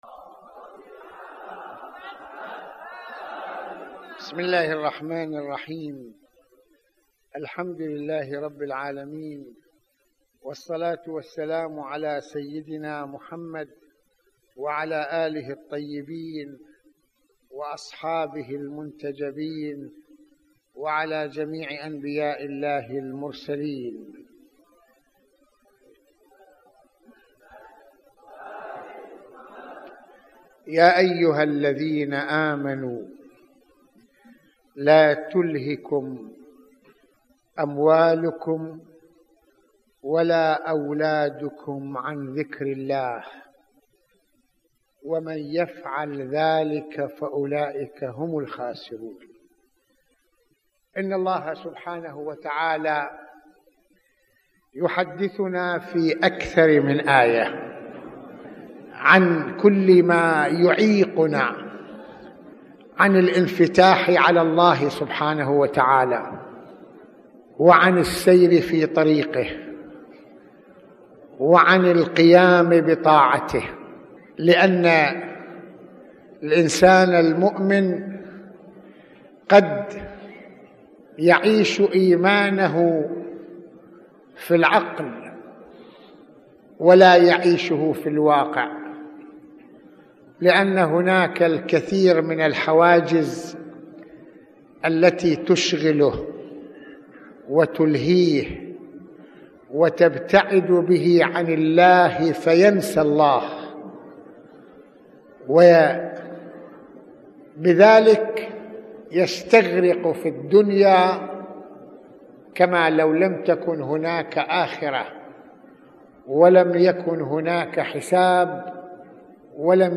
موعظة ليلة الجمعة المكان : مسجد الإمامين الحسنين (ع)